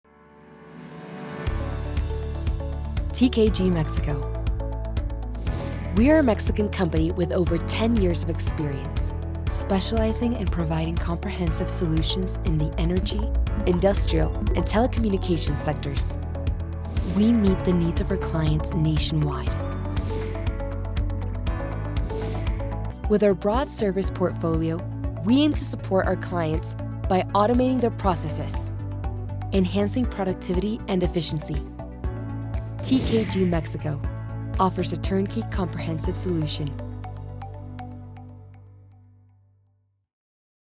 Erklärvideos
Kommerzielle Demo
Home Studio, Rode NT1 Kondensatormikrofon der 5. Generation